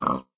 sounds / mob / pig / say3.ogg